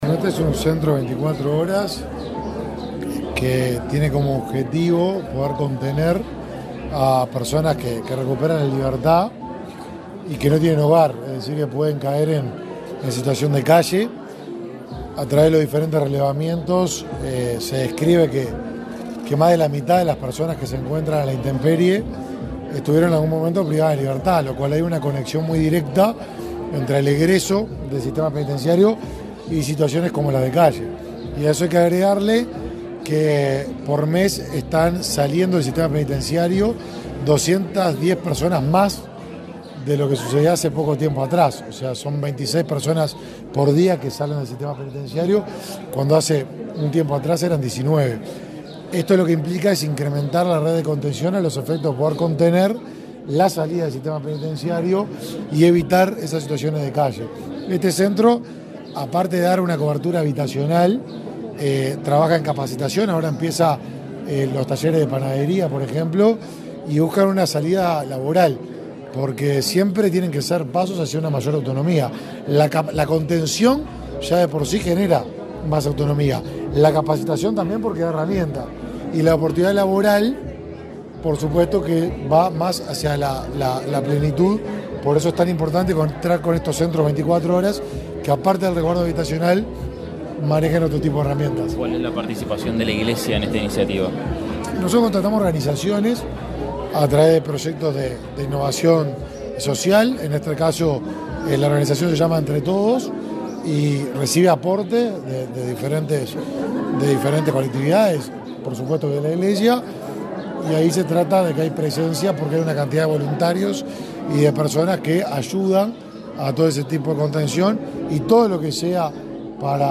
Declaraciones del ministro de Desarrollo Social, Martín Lema
Declaraciones del ministro de Desarrollo Social, Martín Lema 15/08/2023 Compartir Facebook X Copiar enlace WhatsApp LinkedIn El Ministerio de Desarrollo Social (Mides) inauguró un hogar para personas en situación de calle en Montevideo. El titular de la cartera, Martín Lema, dio detalles a la prensa acerca de las características de este centro de 24 horas.